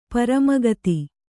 ♪ parama gati